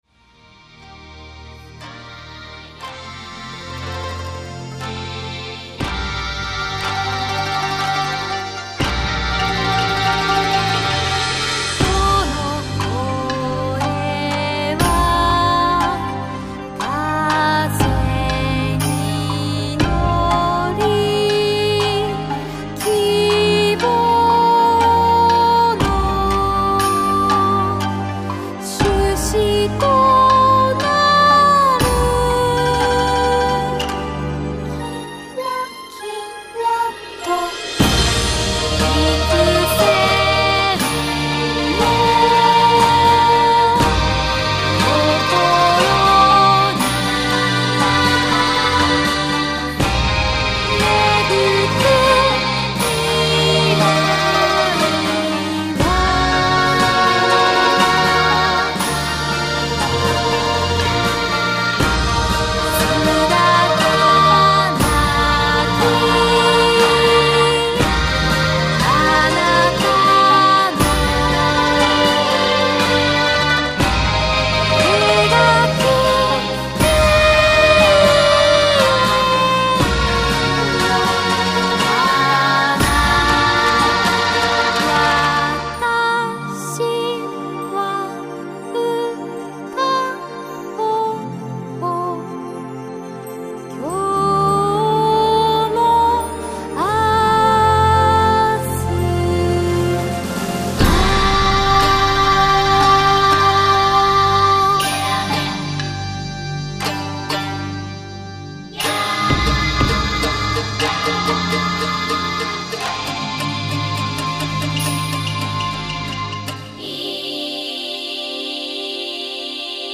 ちょっと和風な、そして儀式っぽい感じの曲に仕上がっています
この曲では、多重コーラスを雅楽の「ショウ」という楽器に見 立てて歌っています。